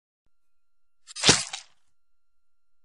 جلوه های صوتی
دانلود صدای چاقو زدن 2 از ساعد نیوز با لینک مستقیم و کیفیت بالا